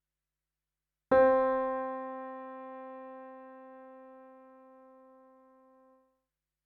Tonic of Melody 1
Ex-1b-Tonic.mp3